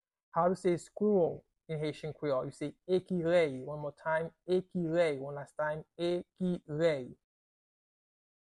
Pronunciation:
How-to-say-Squirrel-in-Haitian-Creole-Ekirey-pronunciation-by-a-Haitian-teacher.mp3